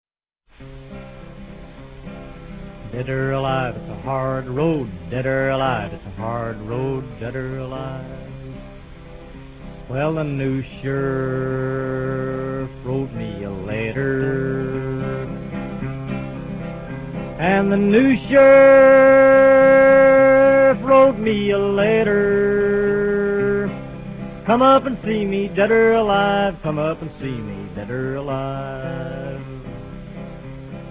Recorded in New York between 1944 and 1949.